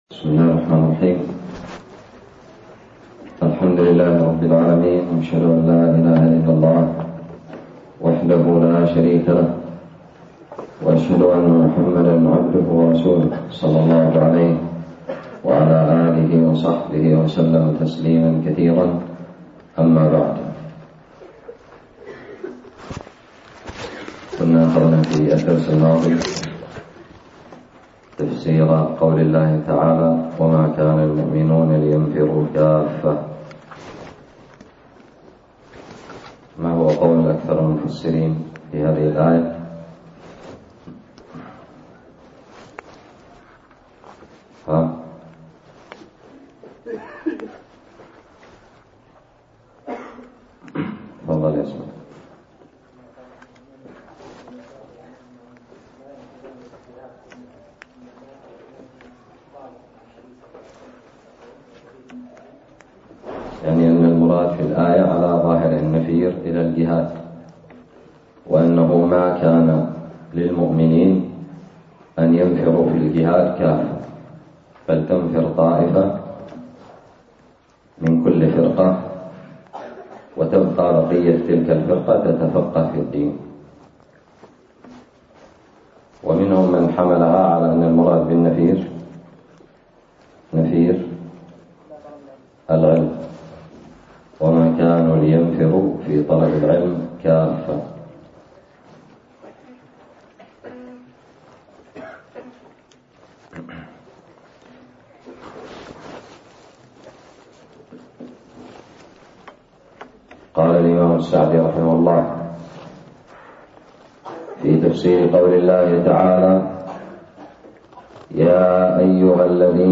الدرس الرابع والخمسون من تفسير سورة التوبة
ألقيت بدار الحديث السلفية للعلوم الشرعية بالضالع